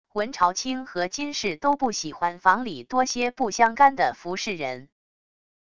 闻朝青和金氏都不喜欢房里多些不相干的服侍人wav音频生成系统WAV Audio Player